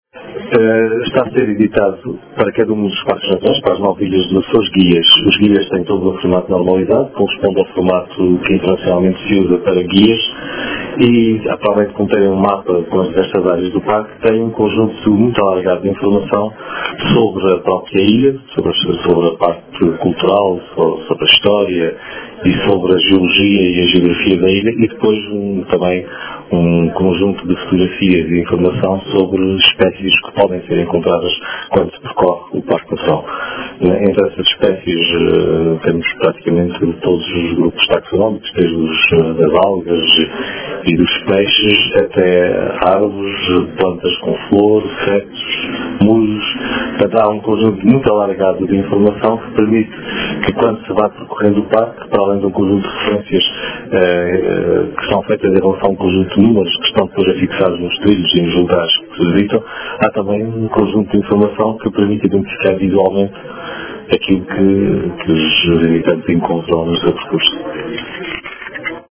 O Secretário Regional do Ambiente e do Mar presidiu esta tarde, no Museu do Vinho, nos Biscoitos, à cerimónia de lançamento do Guia do Parque Natural da Terceira.